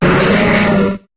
Cri de Smogo dans Pokémon HOME.